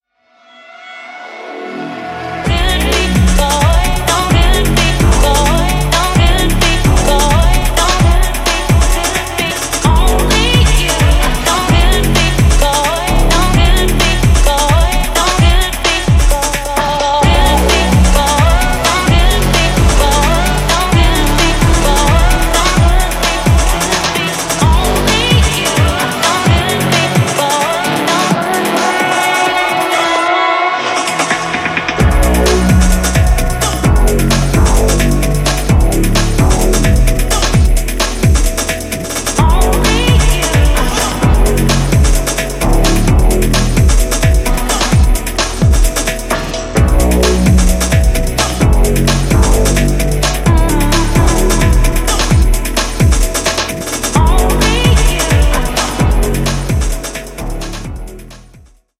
Styl: Disco, House, Techno, Breaks/Breakbeat Vyd�no